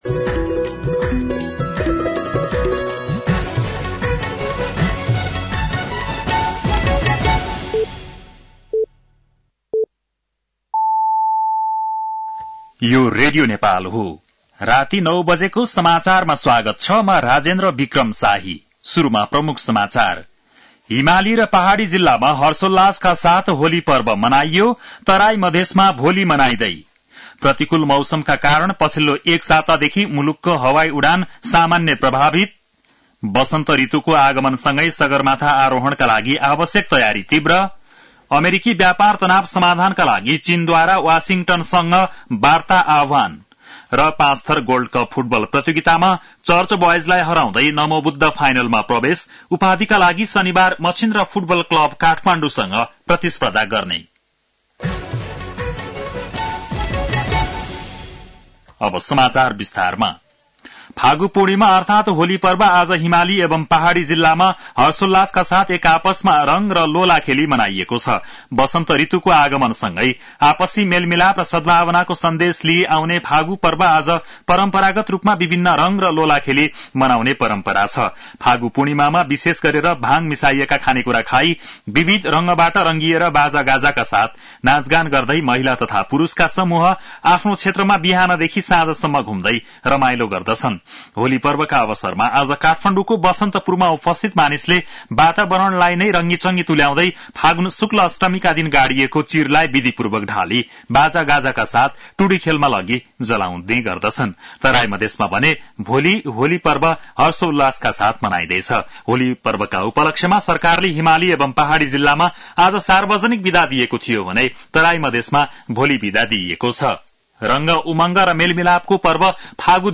An online outlet of Nepal's national radio broadcaster
बेलुकी ९ बजेको नेपाली समाचार : ३० फागुन , २०८१